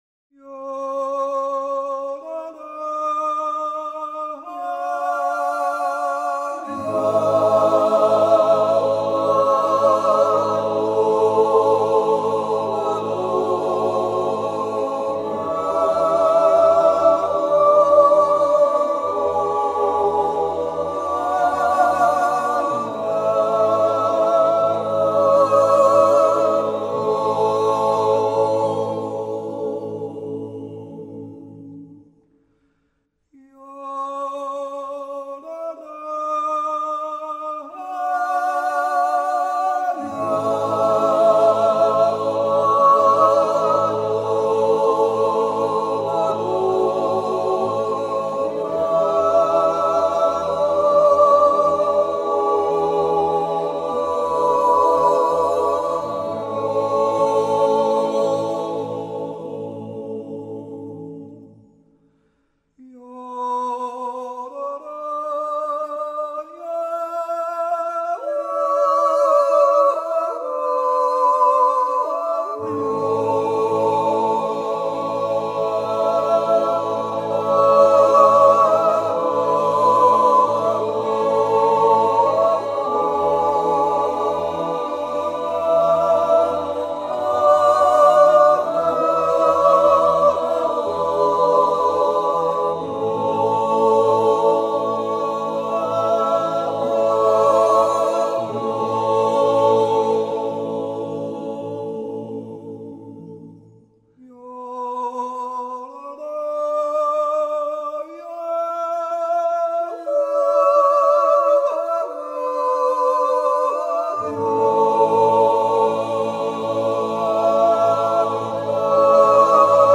yodel group
Natural yodel and yodel songs
instrumental ensemble